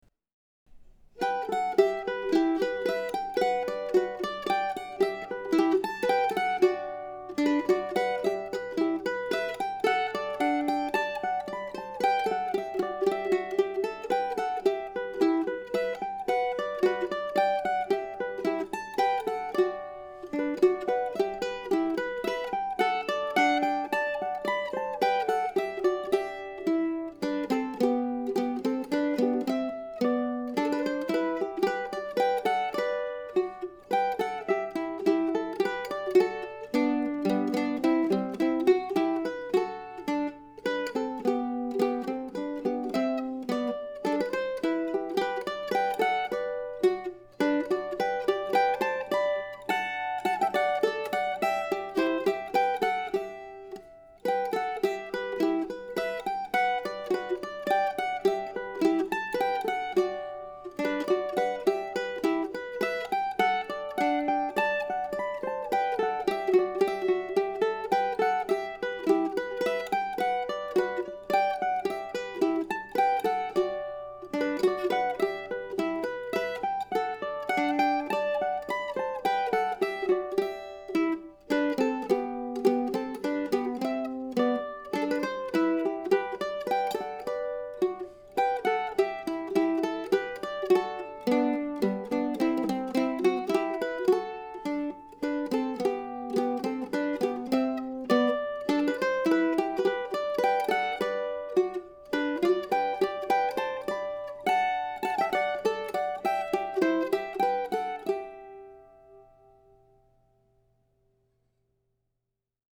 A pretty melody with a simple accompaniment part seems to fit with the title.
This marks the first appearance in So Many Tunes of a recently acquired, century-old Gibson mandolin.
Often that makes an old Gibson less desirable but I like the sound of this one.